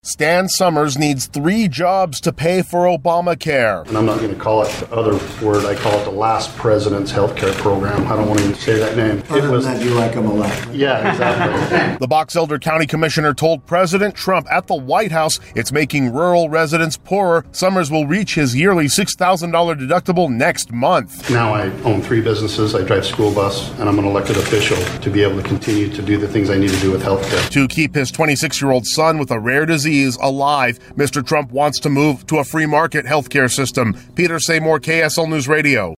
Commissioner Stan Summers tells President Trump at the White House that the Affordable Care Act is crushing him financially.